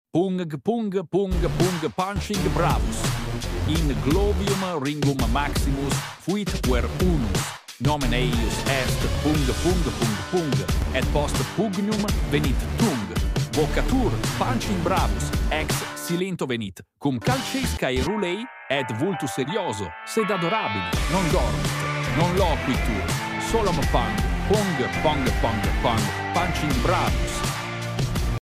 PUNG PUNG PUNG PUNG... PUNCHING sound effects free download